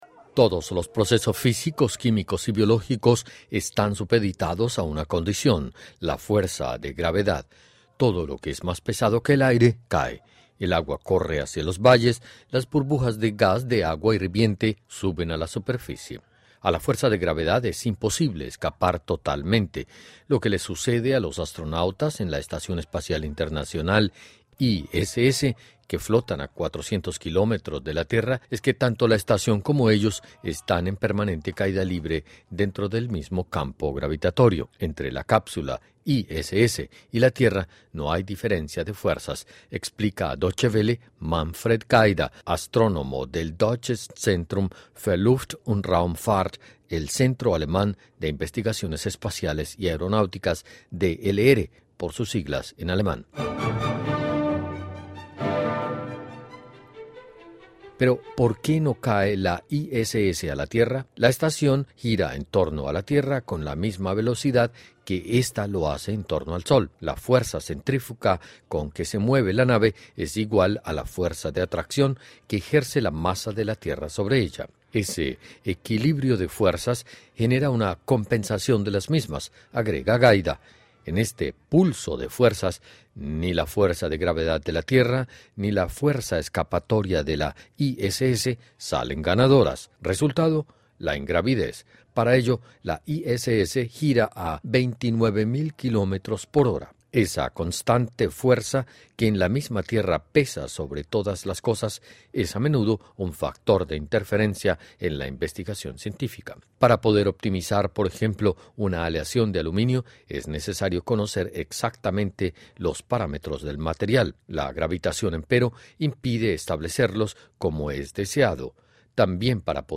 Los llamados vuelos parabólicos fueron concebidos para los astronautas que vayan a viajar fuera de la tierra. Escuche el informe de la radio Deutsche Welle.